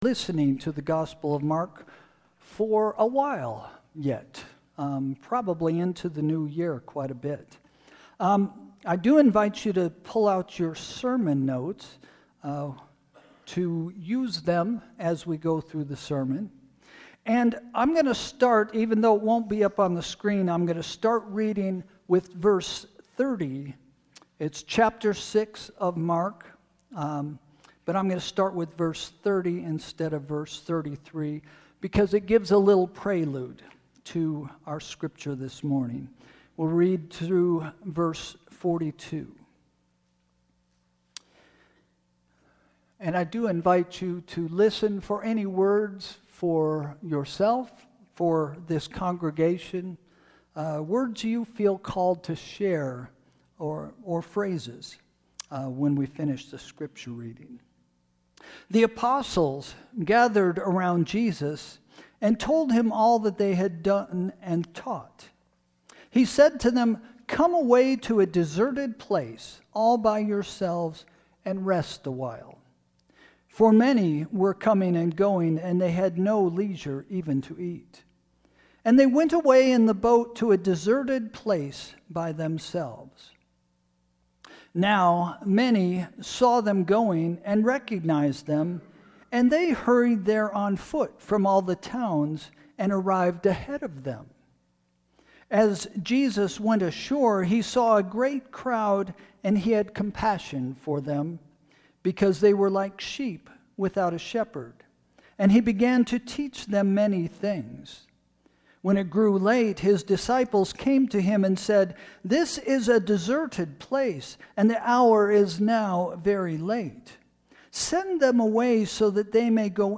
Sermon – October 5, 2025 – First Christian Church